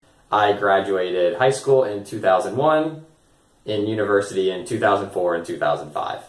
Потренируйте свое восприятие на слух как на английском читаются следующие годы: 1900, 2000, 2018, 1602, 1983 и т.д. В конце статьи, я разместила аудио упражнения, прослушав которые, постарайтесь услышать, какие даты произнесены носителями языка.